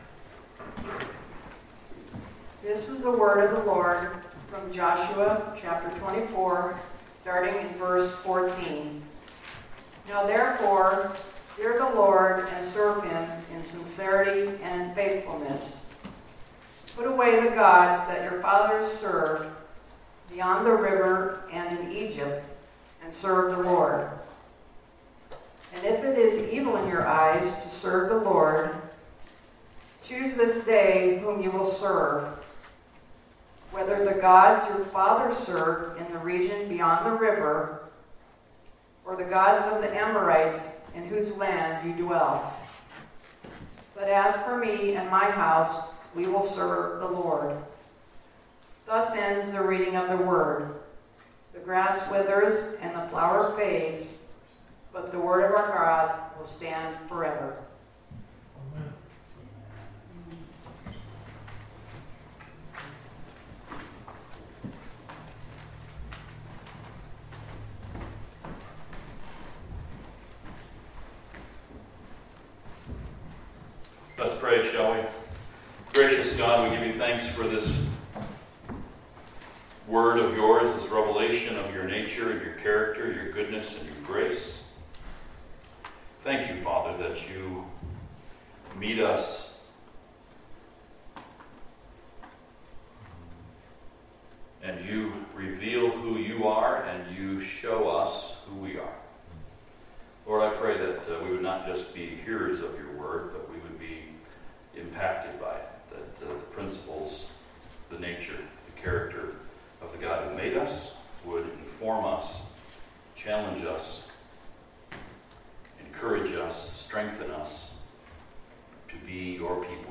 Service Type: Sunday Morning Topics: Incline your hearts , Put away false gods , Serve the LORD , The stones cry out , Yahweh saves « Joshua 23 1 John »